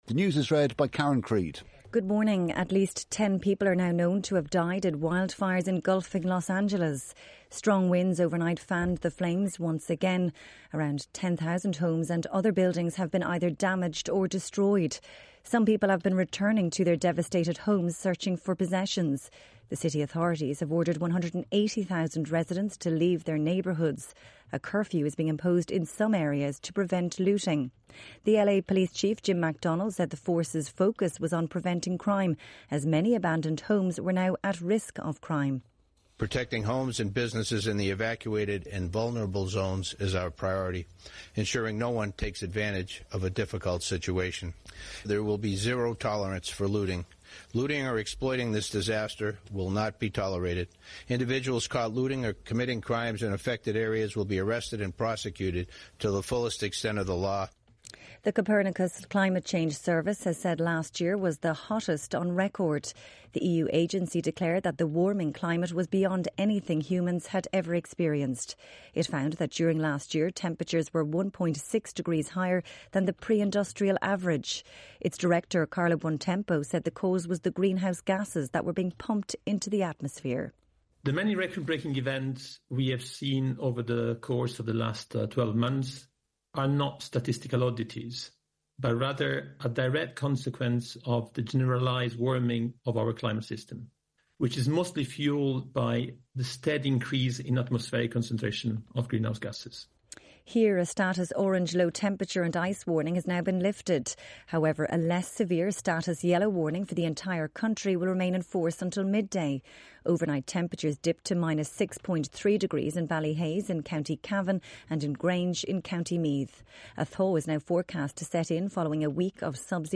8am News Bulletin